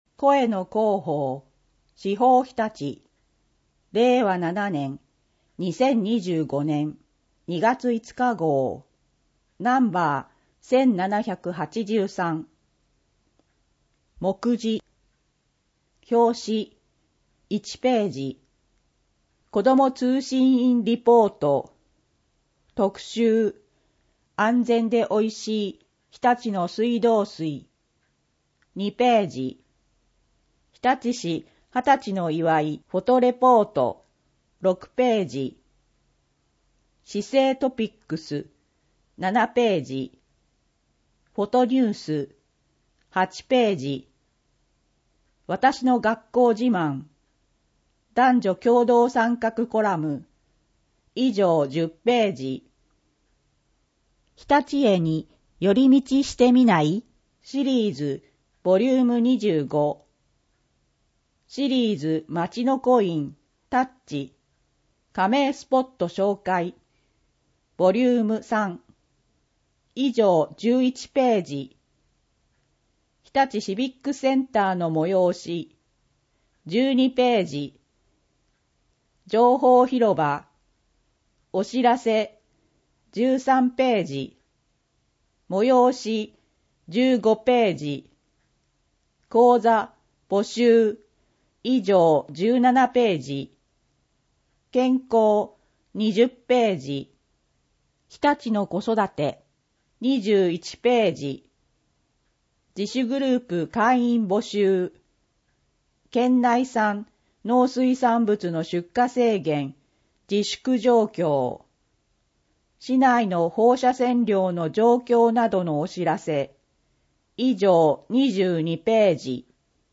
SEAFOOD KITCHEN 24ページ （PDF 612.5KB） 電子書籍 イバラキイーブックス （外部リンク） 声の市報 声の市報を読みあげます。